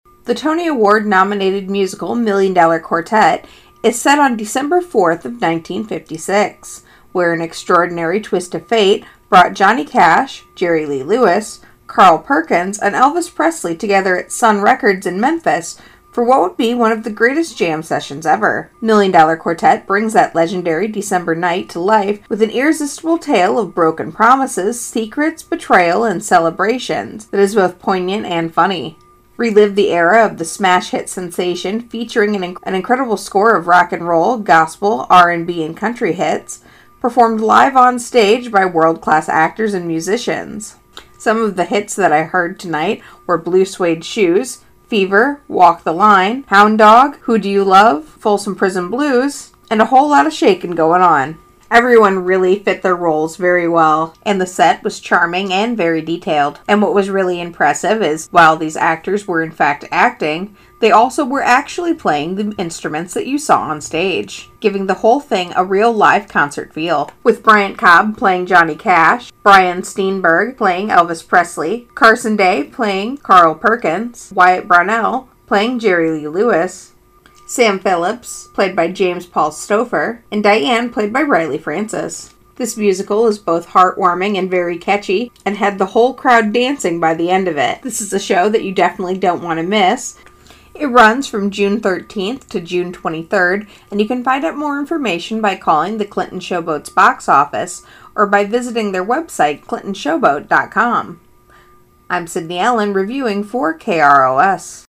Million Dollar Quartet Review